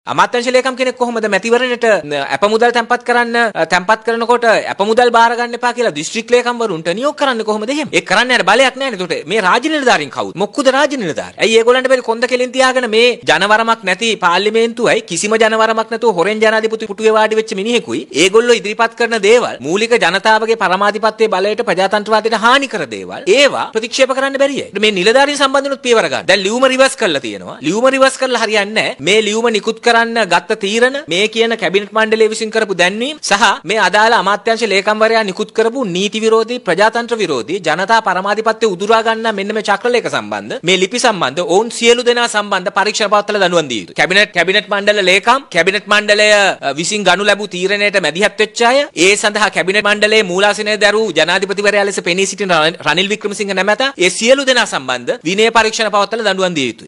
අද පැවති මාධ්‍ය හමුවකදී ඔහු මේ බව සදහන් කළා .